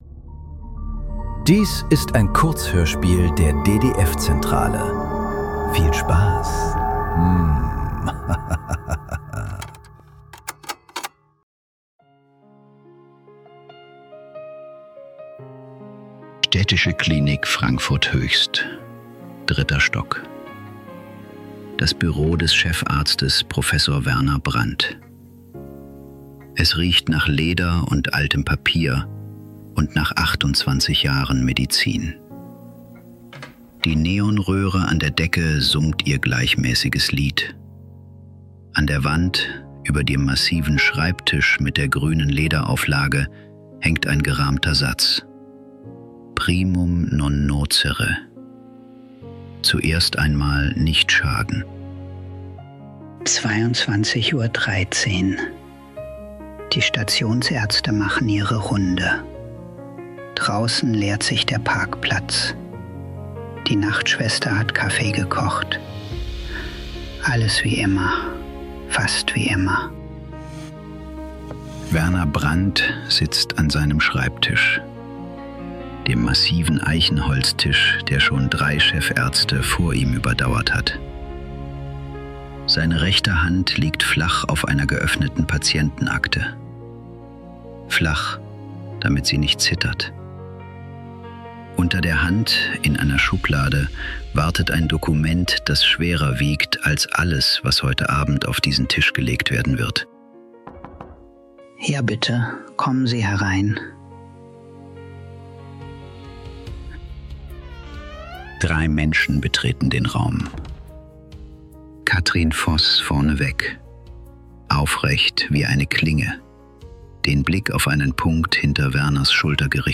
Die Schuld der Gerechten ~ Nachklang. Kurzhörspiele.